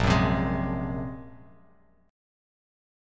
Bb7sus2#5 chord